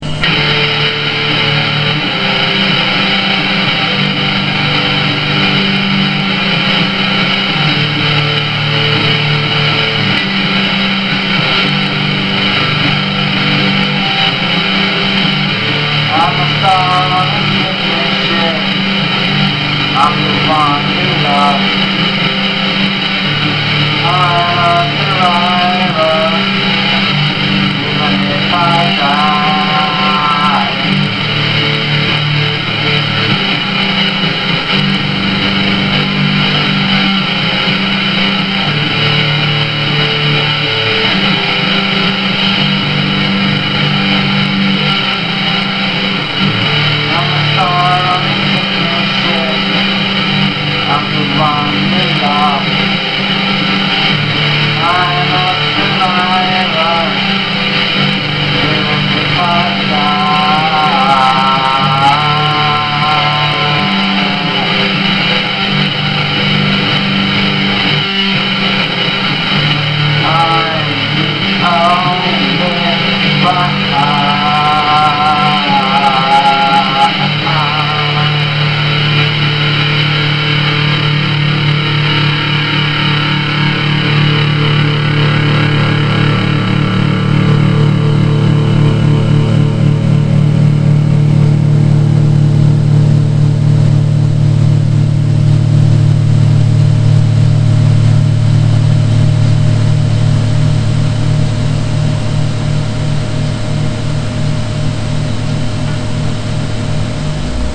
Some really noizy and cool wave-files done by me (in first take with fuck-ups and whatevers included):
ELECTRIC
Sarcastic song about Leo...A quiet song...good one too...